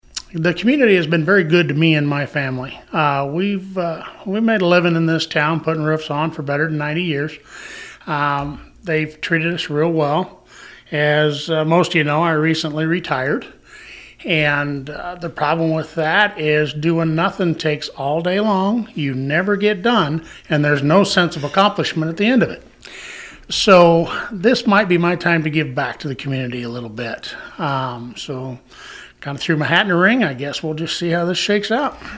In a previous interview, Clauson stated he felt it was his time to give back to the community.